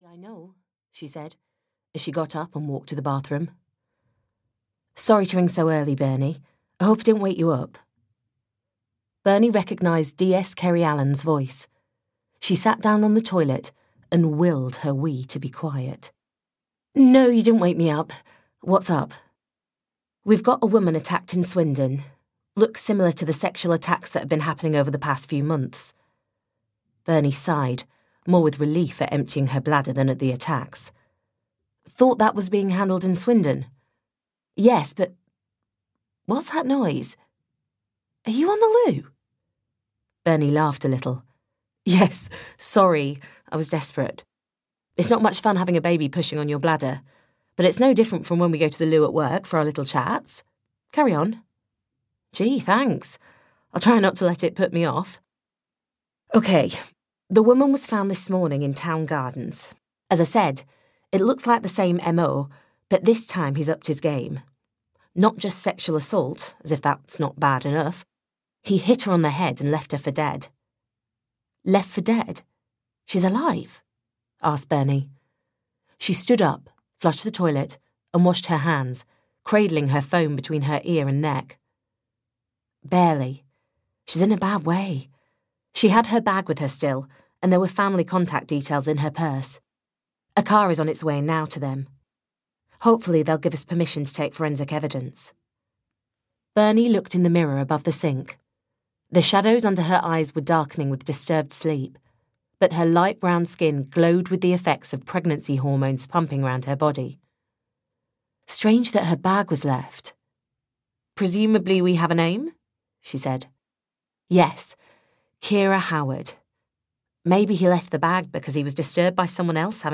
Left for Dead (EN) audiokniha
Ukázka z knihy